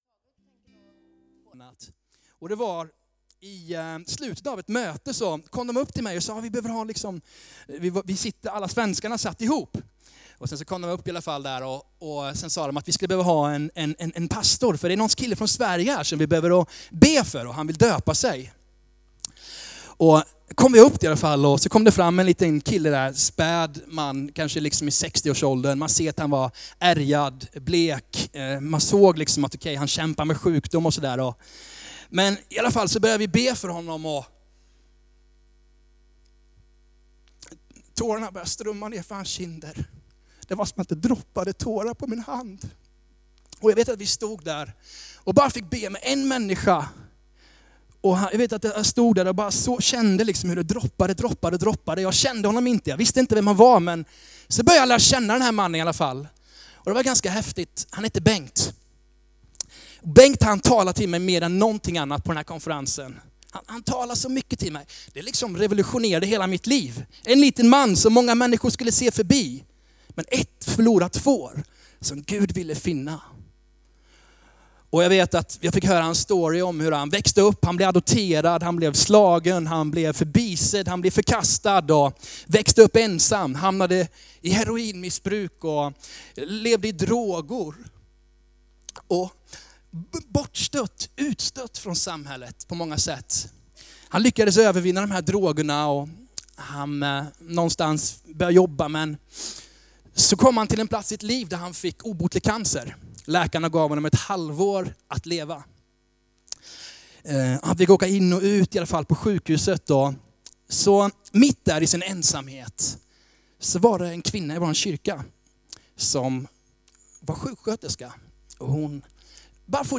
A predikan from the tema "Vi."